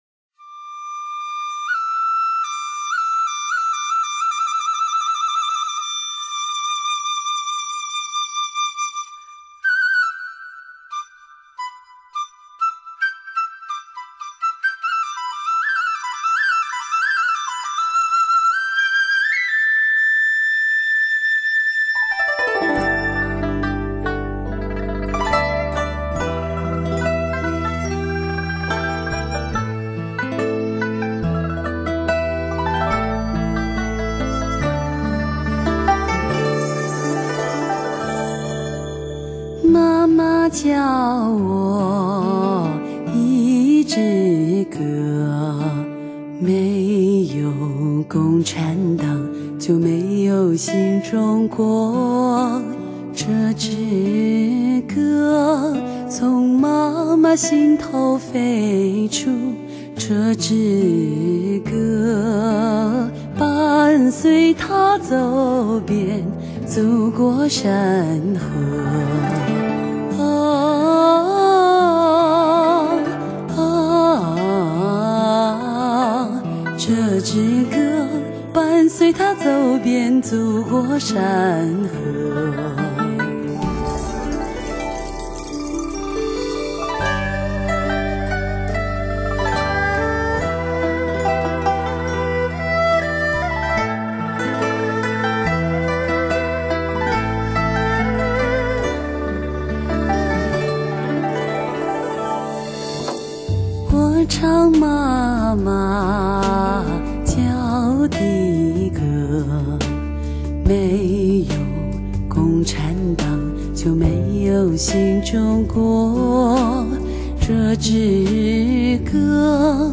磁性女性低频震撼心魄。
民族风格，流行和民歌翻唱。